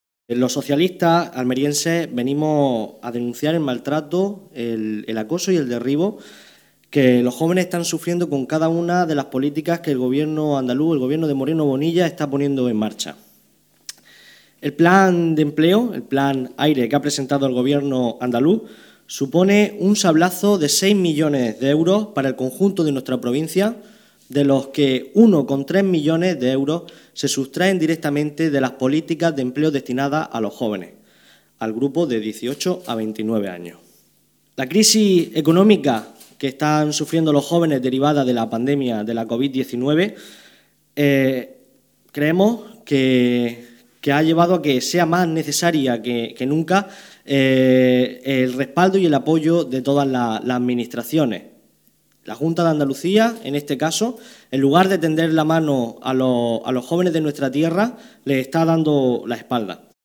Rueda de prensa de Juventudes Socialistas sobre los recortes en empleo juvenil